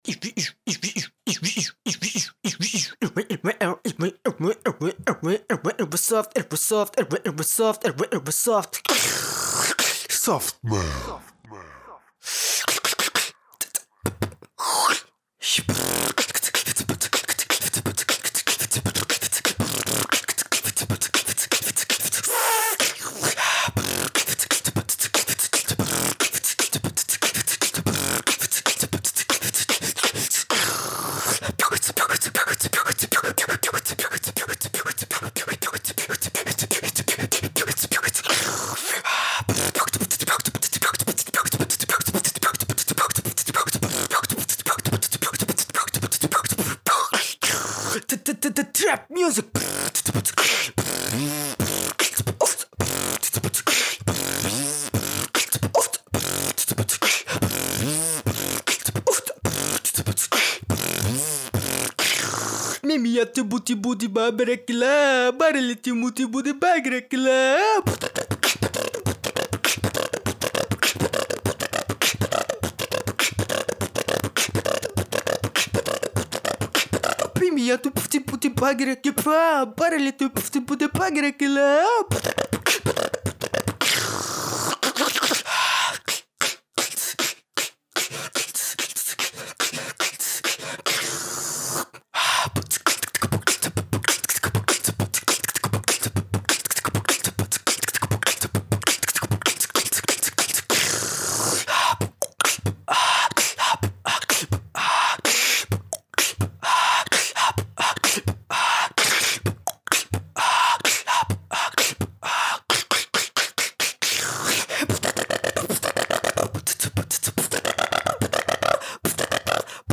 3D spatial surround sound "Beatbox"
3D Spatial Sounds